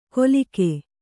♪ kolike